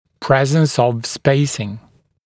[‘prezns əv ‘speɪsɪŋ][‘прэзнс ов ‘спэйсин]наличие трем, свободных промежутков